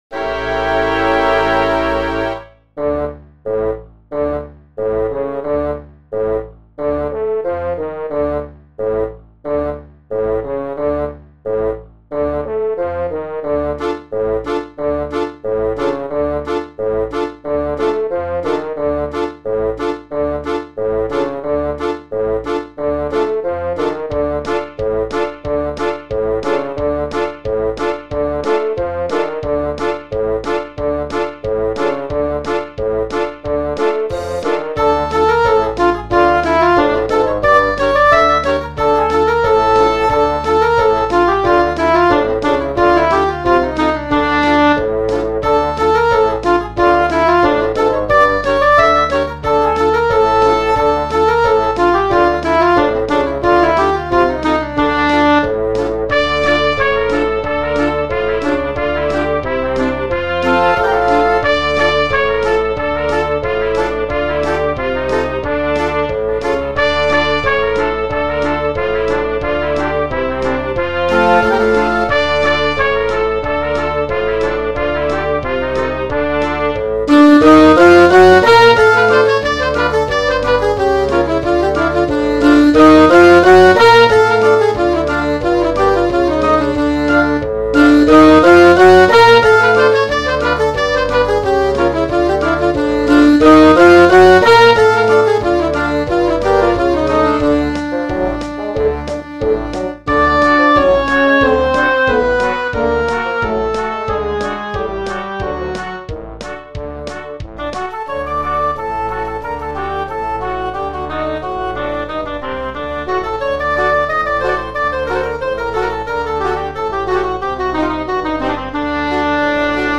Fluit
Klarinet Bb 1
Altsax Eb 1
Fagot 1
Hoorn in F
Trompet in Bb
Trombone
Contrabas – Basgitaar
Drums